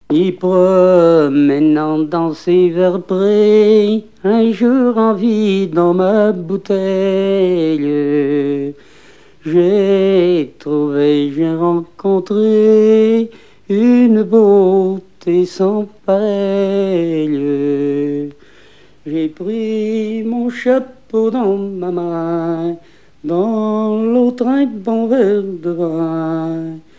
Genre strophique
collecte du répertoire de chansons
Pièce musicale inédite